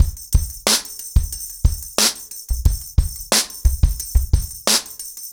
ROOTS-90BPM.9.wav